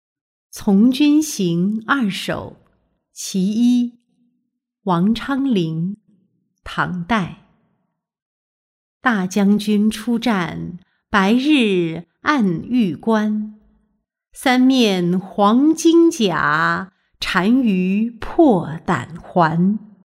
从军行二首·其一-音频朗读